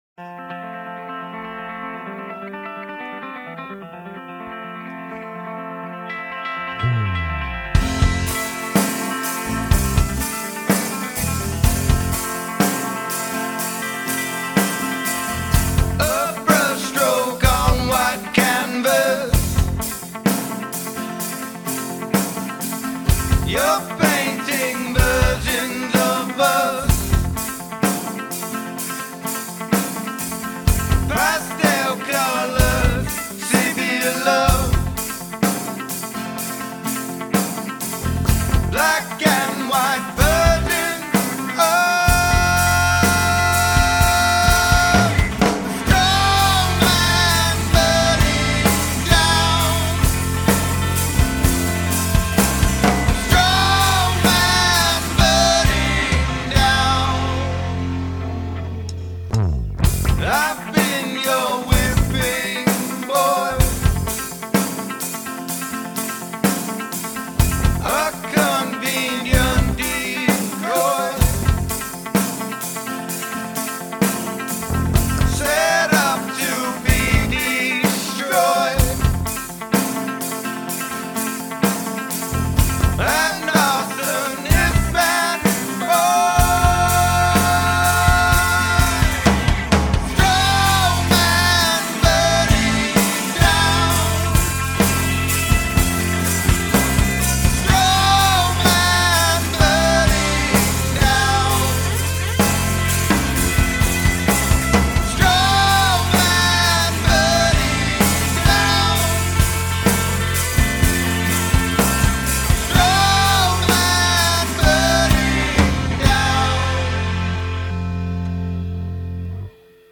Here it is live on WDVE Coffee House: